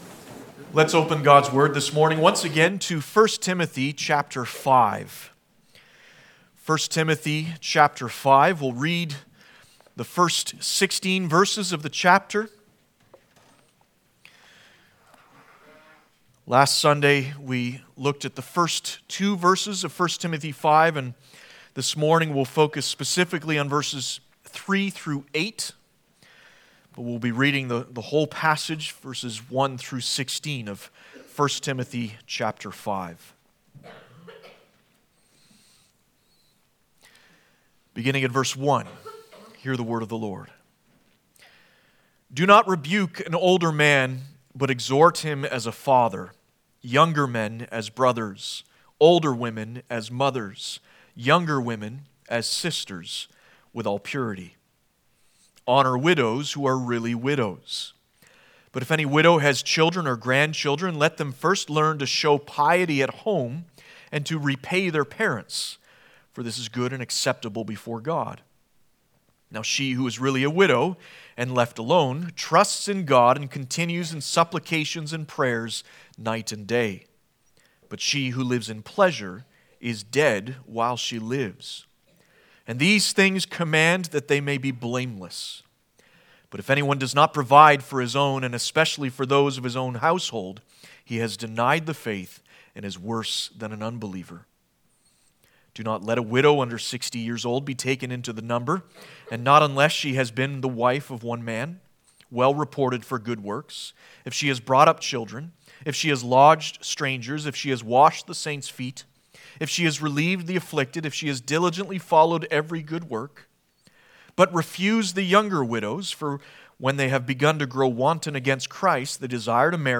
Passage: 1 Tim 5:1-16 Service Type: Sunday Morning « All things are possible Jesus said